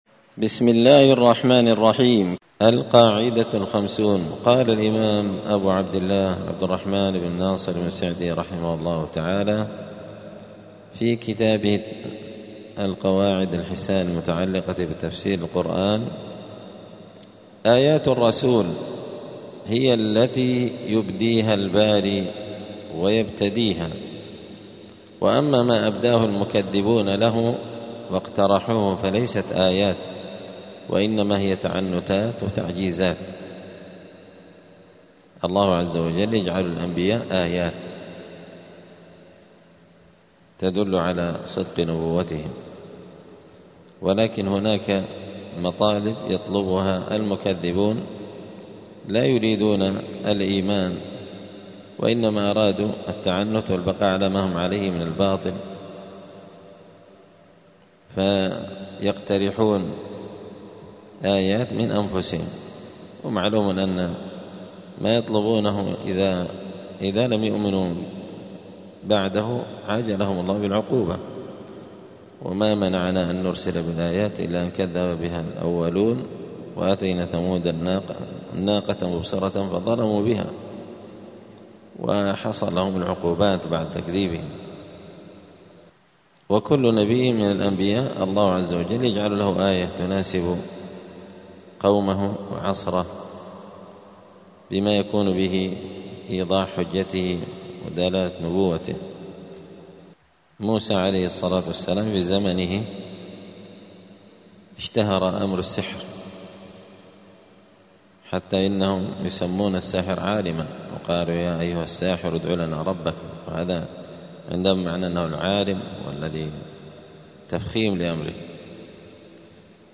دار الحديث السلفية بمسجد الفرقان قشن المهرة اليمن
الدروس اليومية